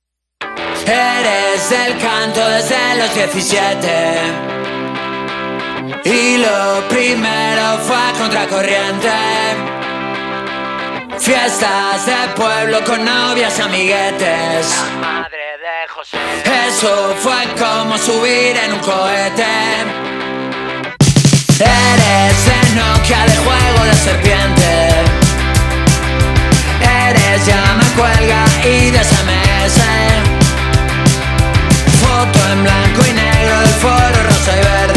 Жанр: Рок
Rock, Latin, Pop Latino